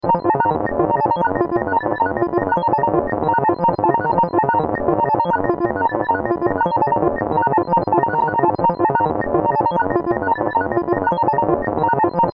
Digital Confusion Sound Effect
This loop creates a chaotic and unsettling electronic noise for sci-fi, experimental, or abstract projects.
Use it anywhere you need a harsh, confusing digital atmosphere.
Digital-confusion-sound-effect.mp3